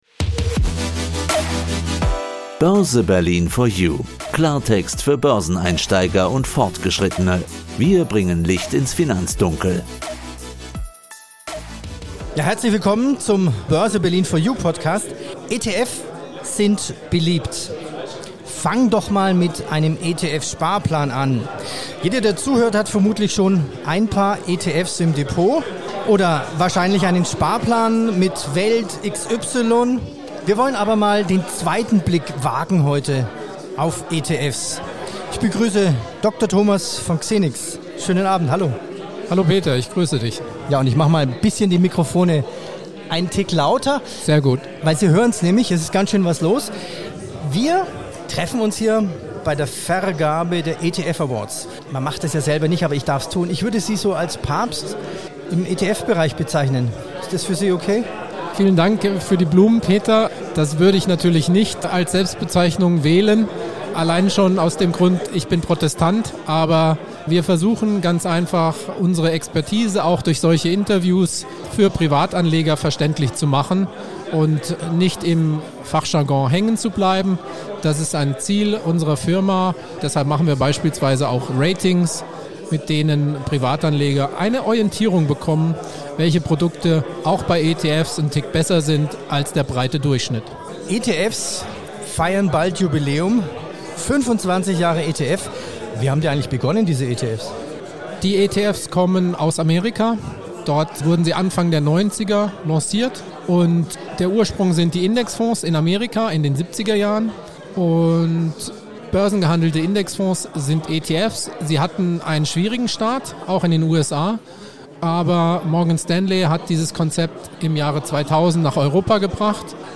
bei der Preisverleihung